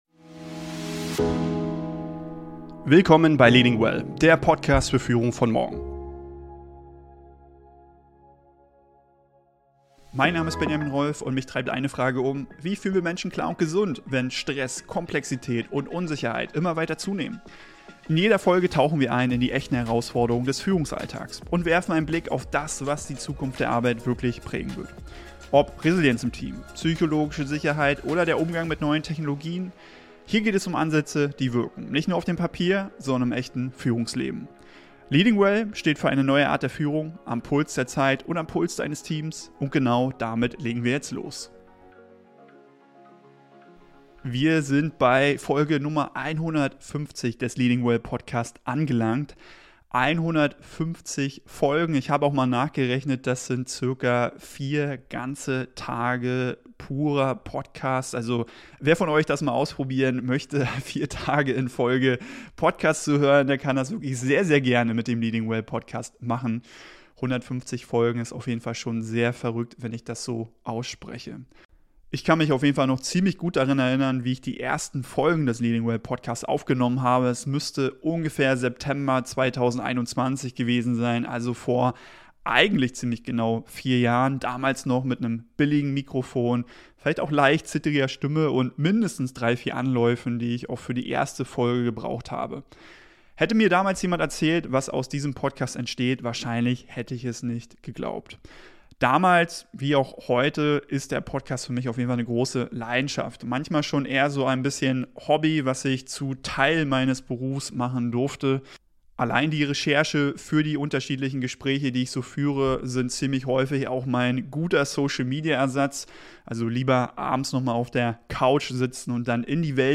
In dieser besonderen Solo-Folge